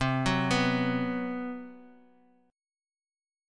B5/C chord
B-5th-C-x,3,4,4,x,x-8.m4a